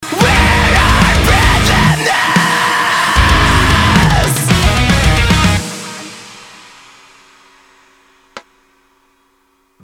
там же в pearl ff 8 из blackbird на римшоте есть звук палки об обод через несколько секунд после самого удара. и такое ощущение что на нескольких семплах Вложения blackbird_pearl_ff8_rimshot.mp3 blackbird_pearl_ff8_rimshot.mp3 307,8 KB · Просмотры